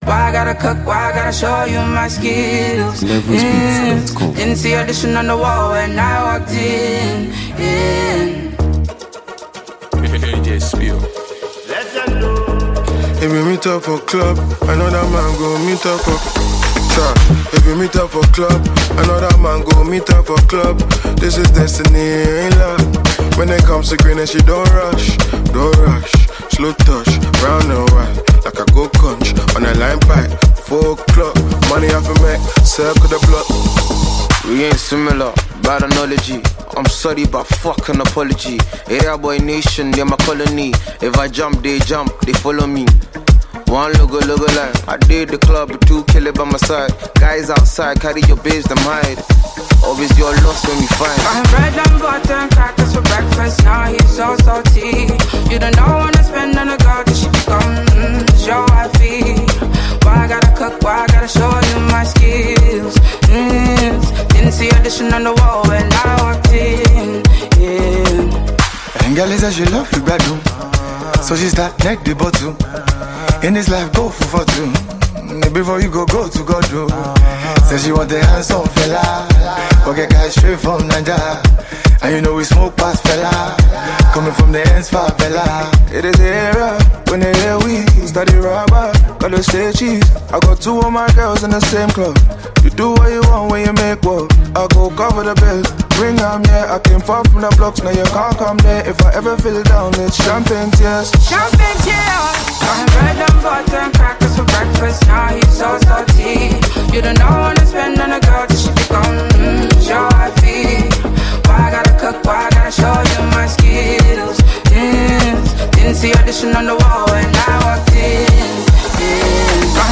whose smooth and soulful vocals add a captivating touch.